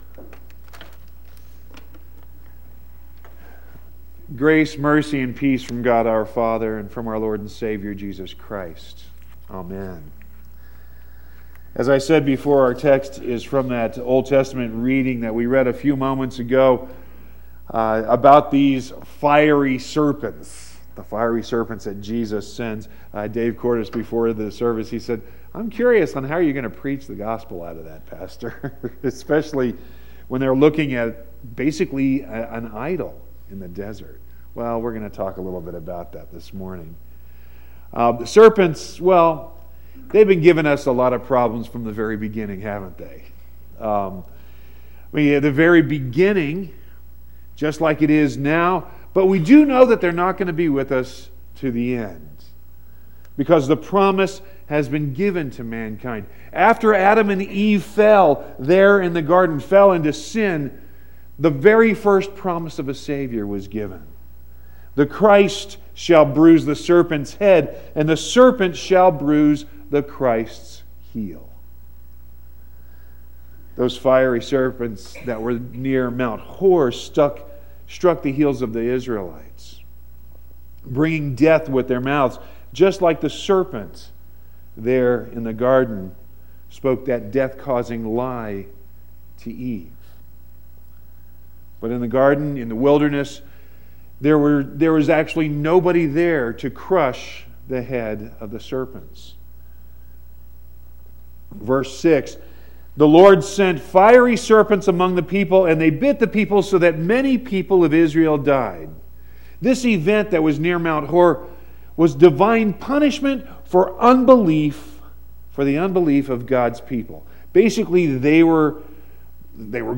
3-11-18-sermon.mp3